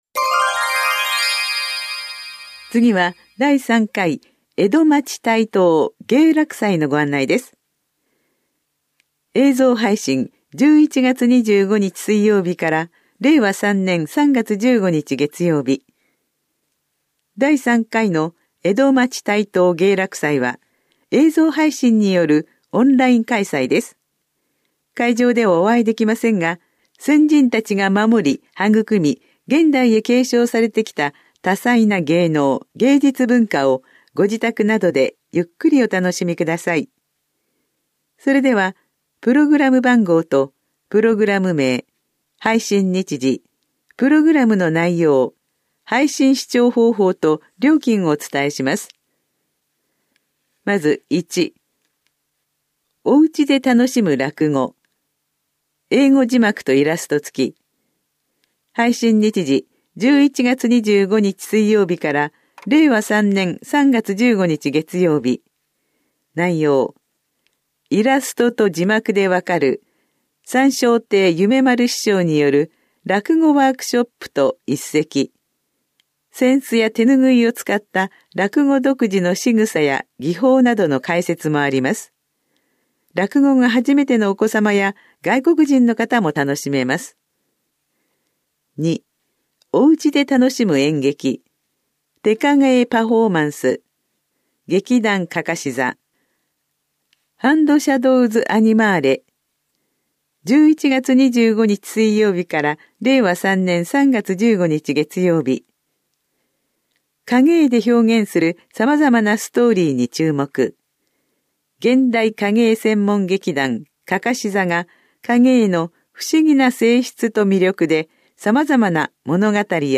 広報「たいとう」令和2年11月5日号の音声読み上げデータです。